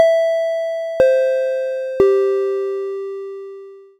3-tone chime DOWN
bell chime ding microphone pa ping ring sound effect free sound royalty free Sound Effects